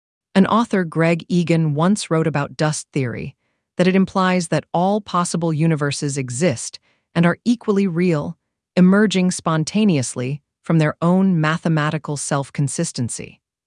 Kokoro-TTS-Zero